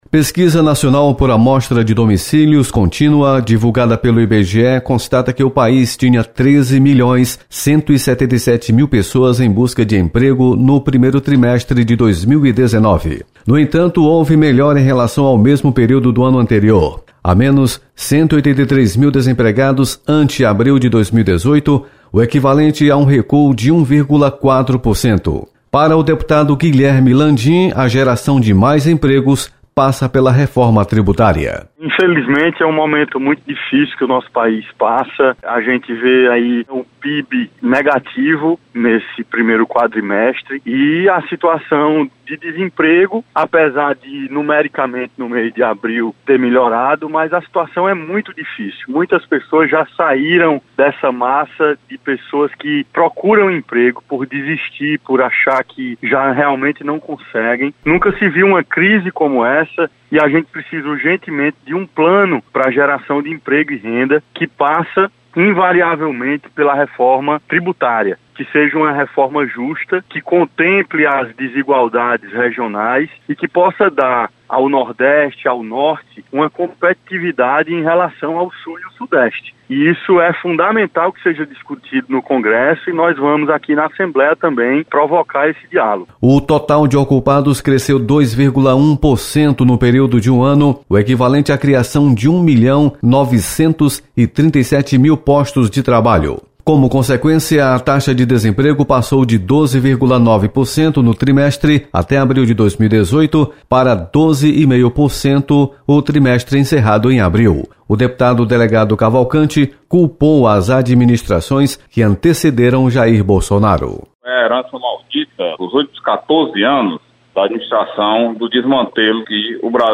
Deputados comentam a respeito do aumento do desemprego.